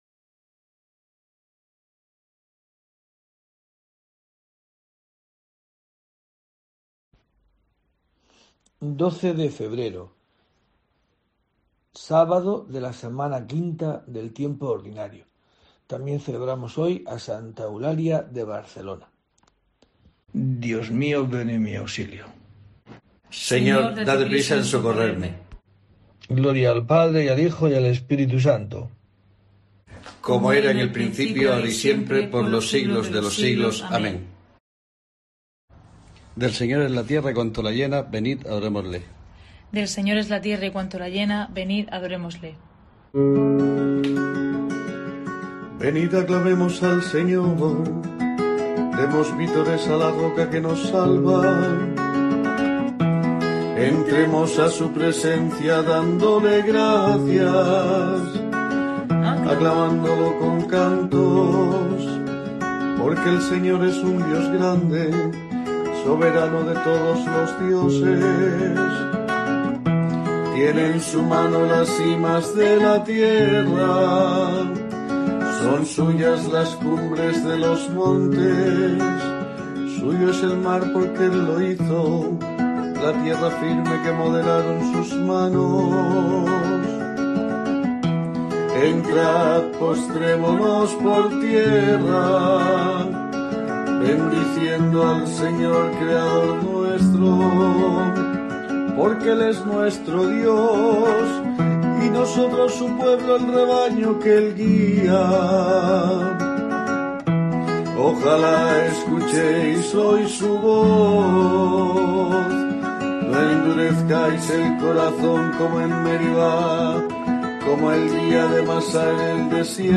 12 de febrero: COPE te trae el rezo diario de los Laudes para acompañarte